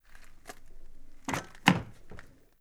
Abrir la puerta de un coche Triumph
Sonidos: Acciones humanas
Sonidos: Transportes